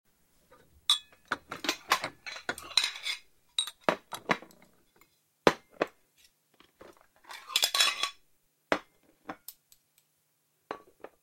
SFX摆放碗筷的声音音效下载
SFX音效